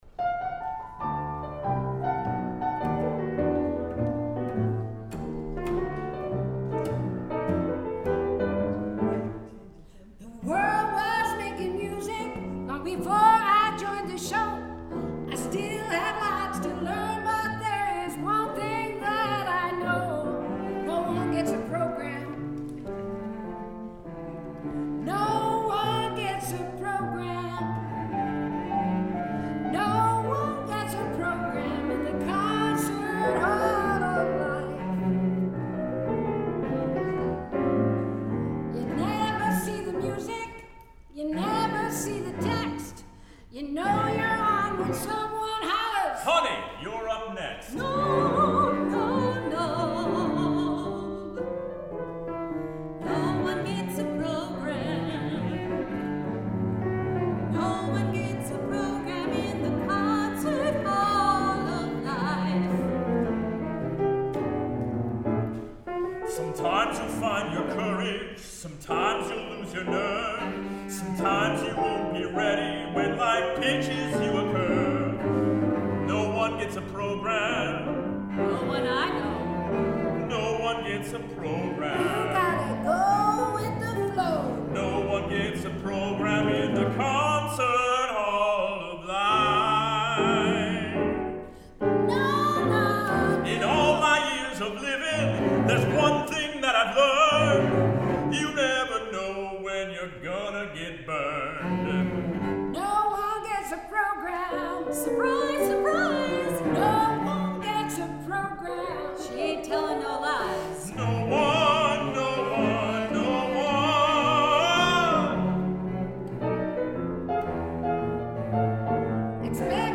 1 or 2 medium voices, piano